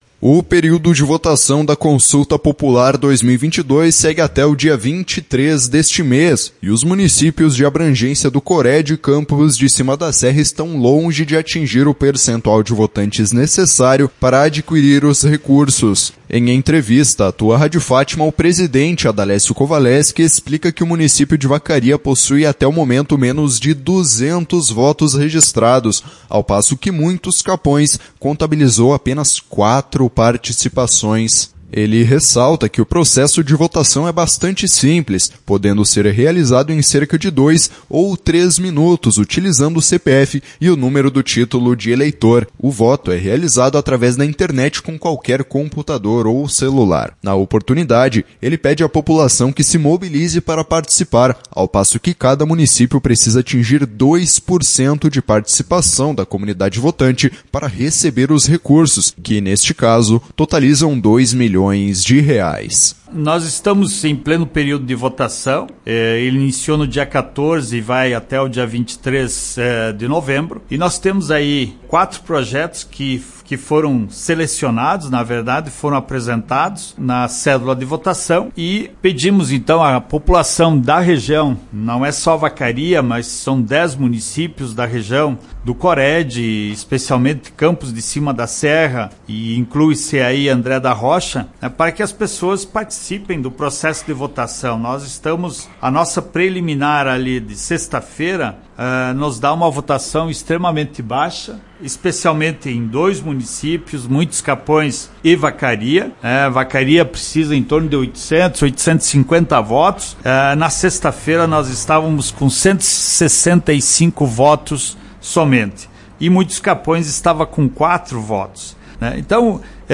Em entrevista à Tua Rádio Fátima